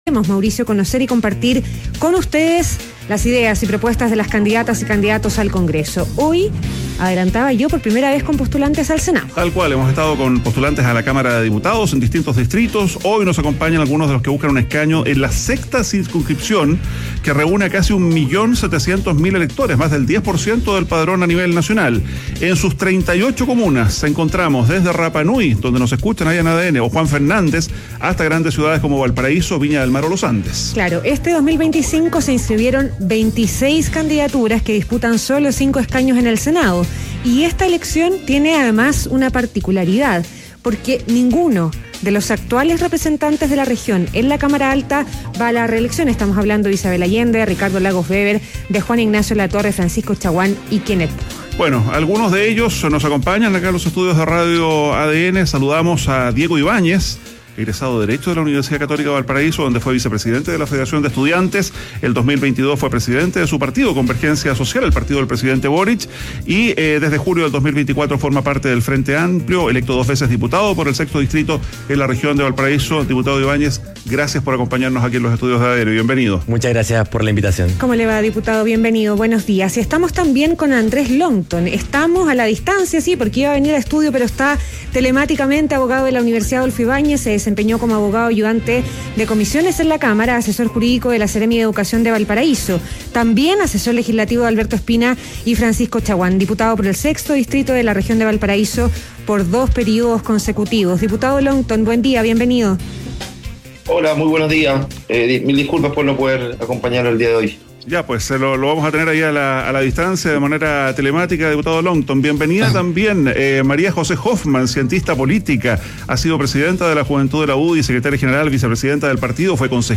Debate parlamentario, Circunscripción senatorial 6 (Valparaíso) - ADN Hoy